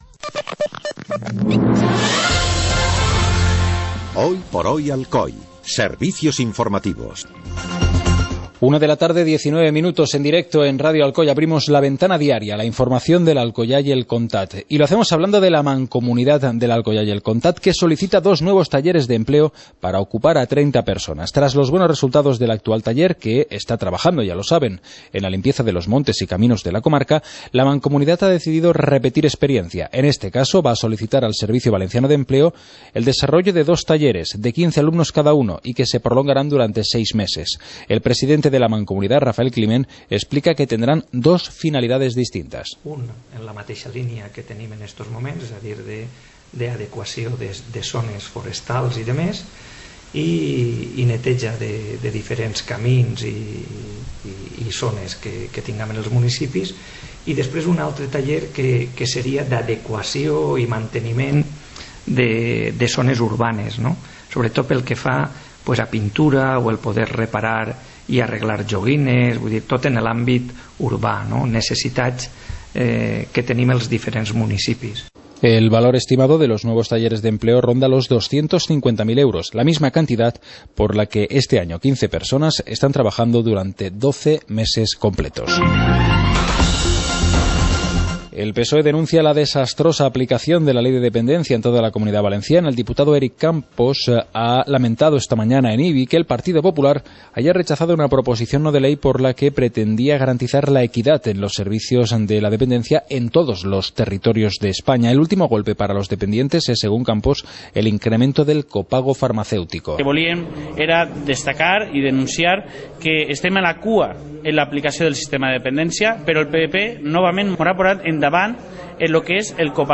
Informativo comarcal - lunes, 23 de junio de 2014